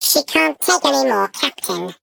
Sfx_tool_spypenguin_vo_max_storage_reached_03.ogg